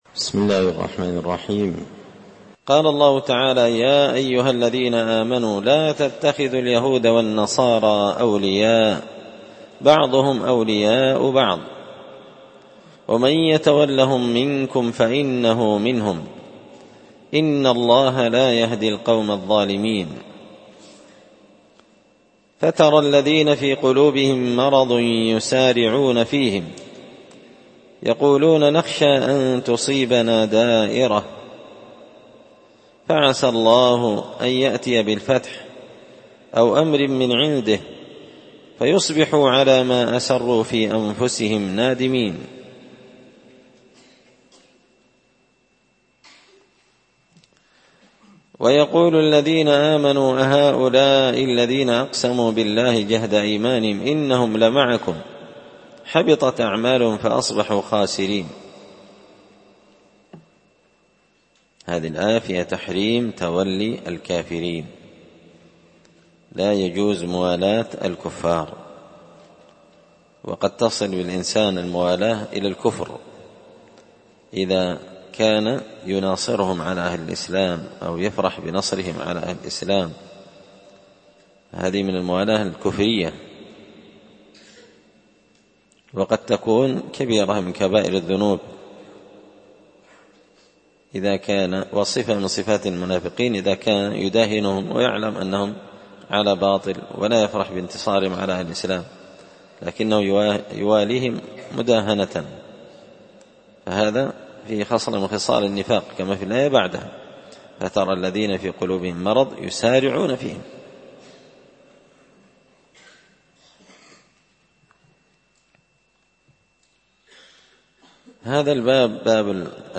📢 ألقيت هذه الدروس في 📓 # دار _الحديث_ السلفية _بقشن_ بالمهرة_ اليمن 🔴مسجد الفرقان